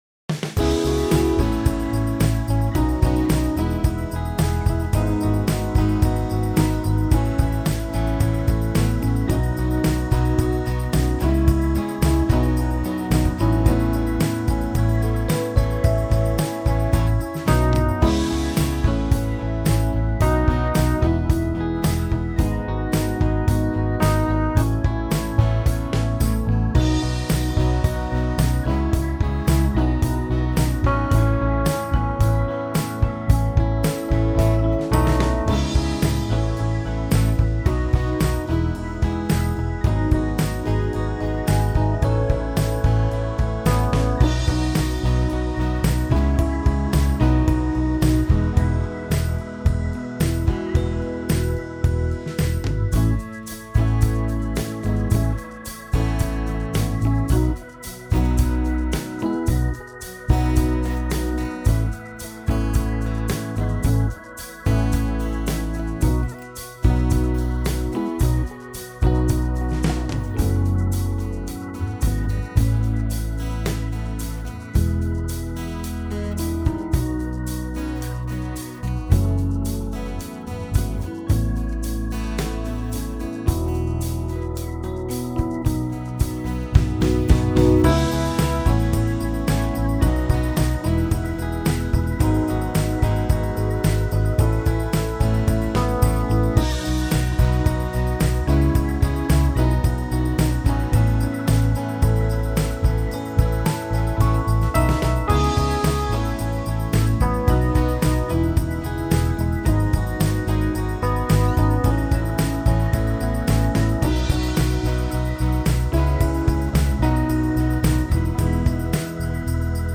I år er der ingen video med fagter - men der er tilgæld en karaokeversion:
Ugens bibelvers - karaoke
Evighedernes-Konge-karaoketrack.mp3